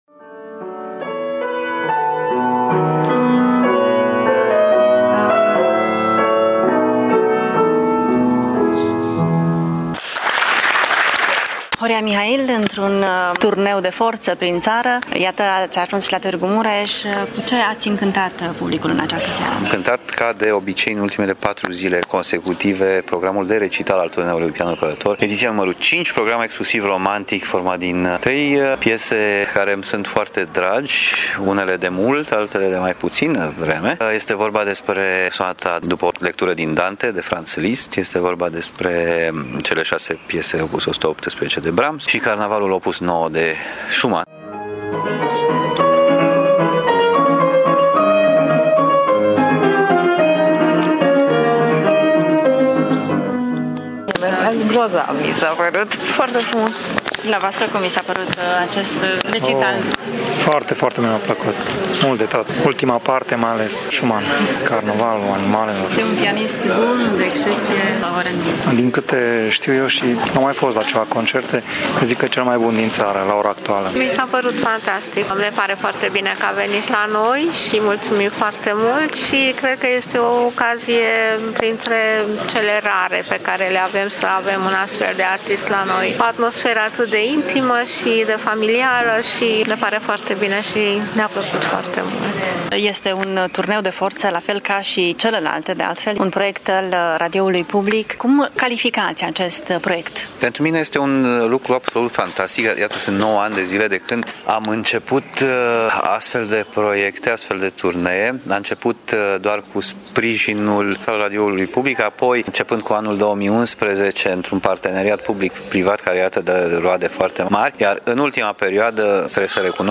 Interviu  Horia Mihail într-un recital de excepție la Tg.Mureș: